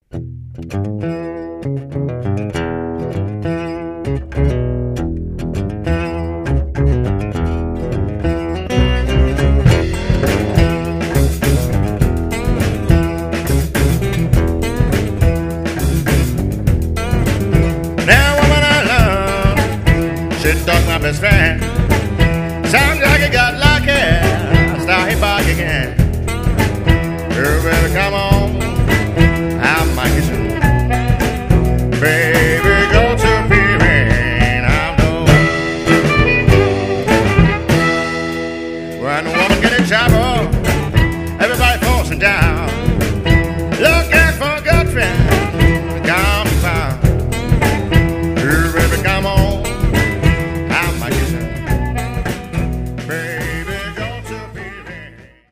violin
vocal, guitar, dobro, slide
guitar, harmonica, vocal